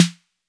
808-Snare21.wav